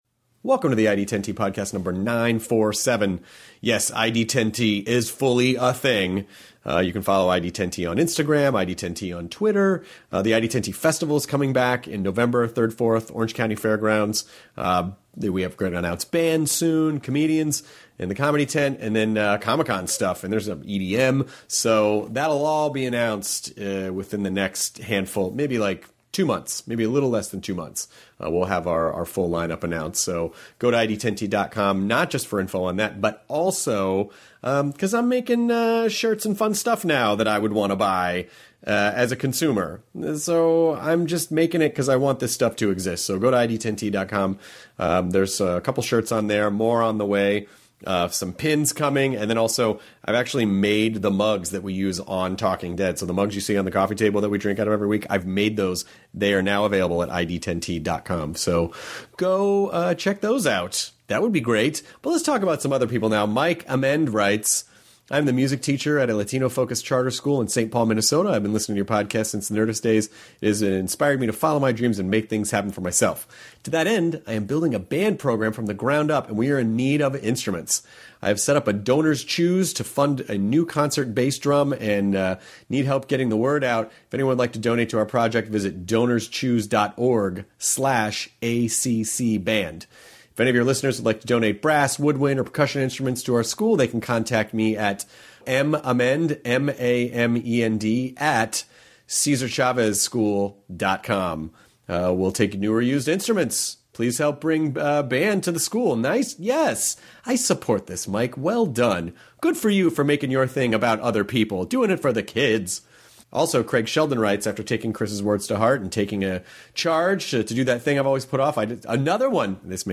Alan Alda (M.A.S.H., Scientific American Frontiers, The West Wing) talks to Chris about recording the audio book of his book If I Understood You, Would I Have This Look On My Face?, teaching scientists and doctors communication skills and how he pursued jobs that interested him personally. Alan also talks about how he got into training people in communication, his love of improv, how M.A.S.H. was able to create comedy out of such a serious topic and why he thinks everyone should work on their communication skills!